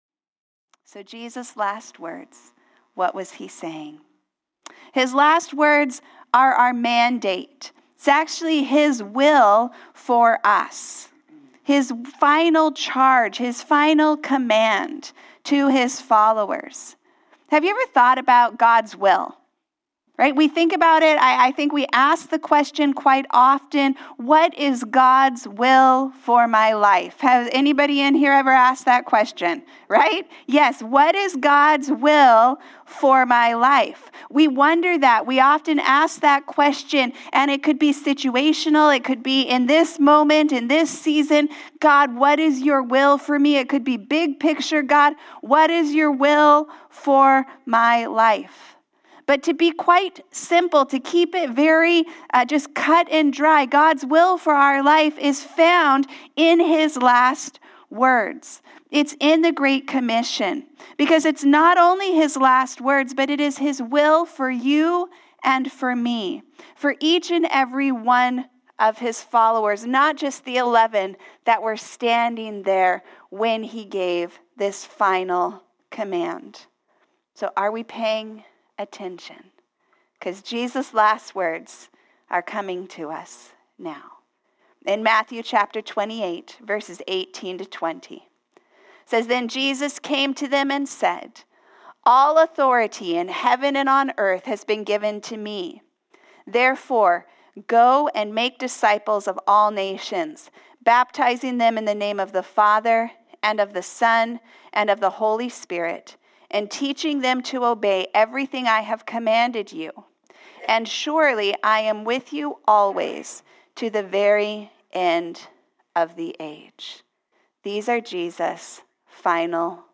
Sermons | Harvest Church